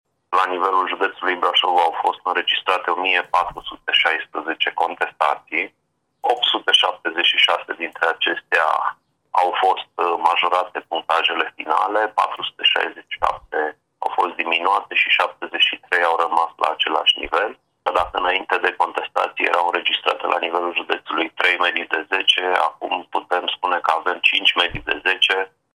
Ovidiu Tripșa, inspector general scolar: